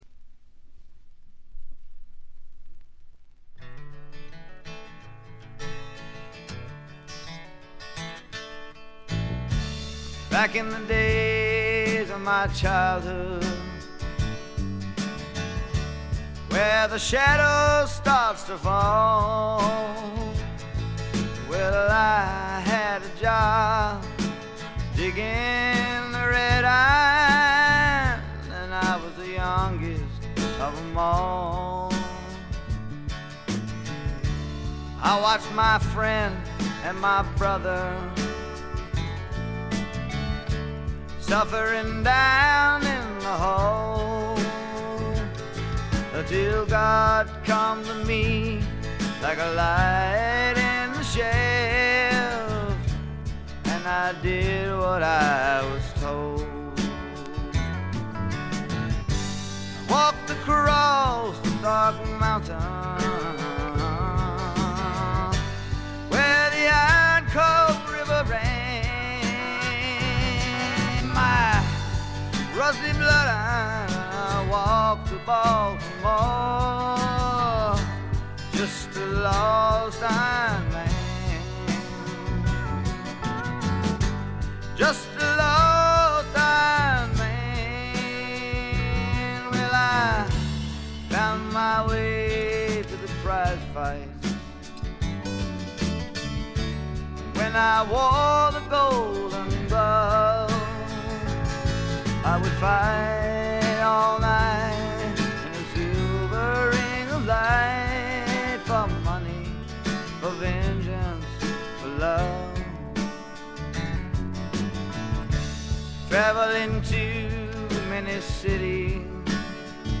ごくわずかなノイズ感のみ。
聴くものの心をわしづかみにするような渋みのある深いヴォーカルは一度聴いたら忘れられません。
試聴曲は現品からの取り込み音源です。